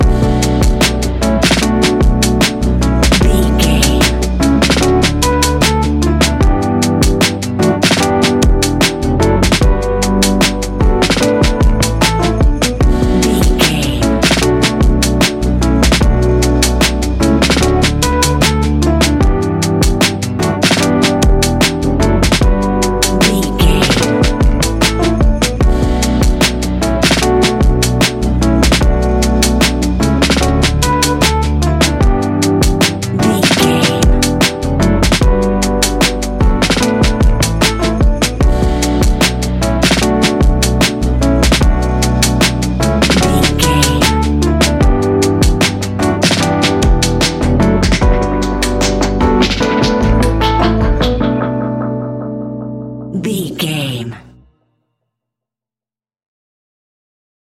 Ionian/Major
B♭
chilled
laid back
Lounge
sparse
new age
chilled electronica
ambient
atmospheric
instrumentals